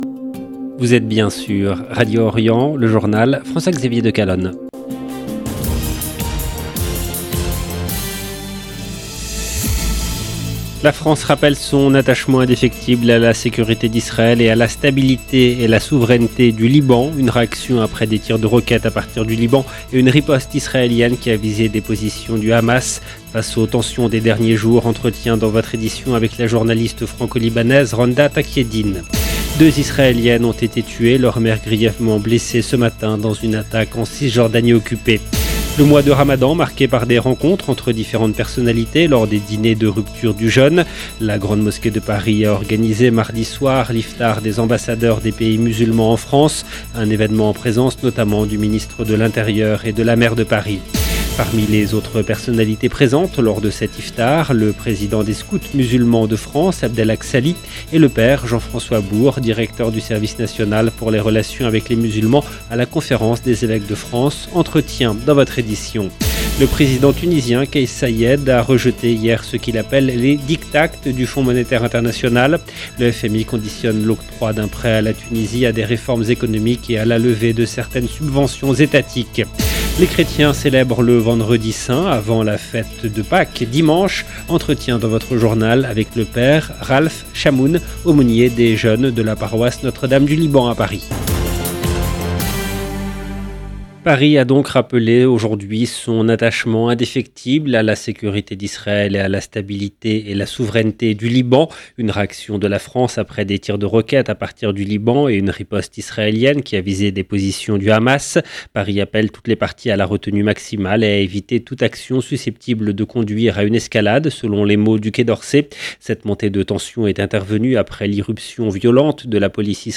Entretiens dans cette édition. Le président tunisien Kais Saied a rejeté hier ce qu’il appelle les "diktats" du Fonds monétaire international.